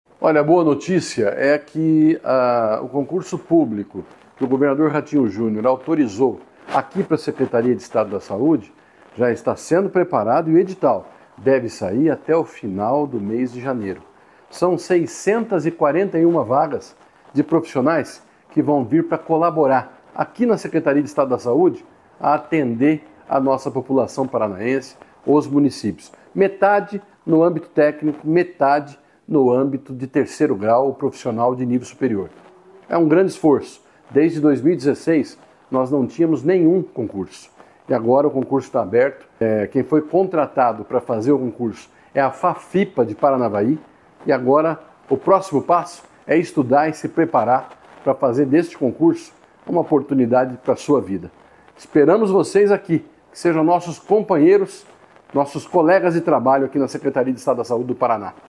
Sonora do secretário da Saúde, Beto Preto, sobre o novo concurso público para a pasta